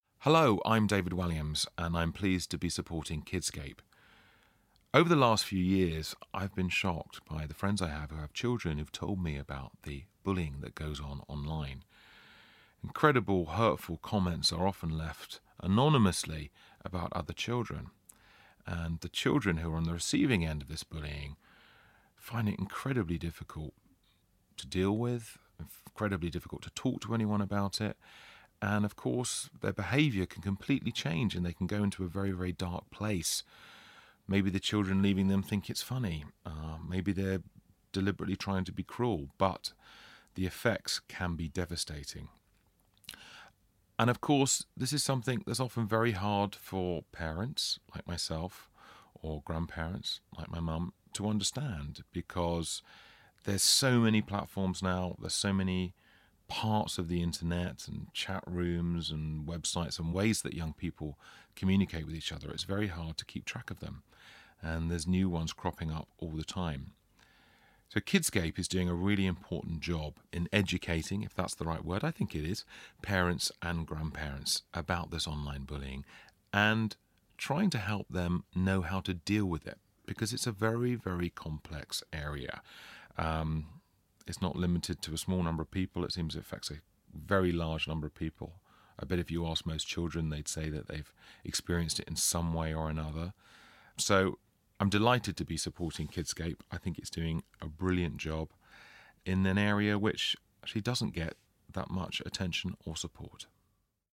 Author and comedian David Walliams on why he supports the work of Kidscape, the anti-bullying charity.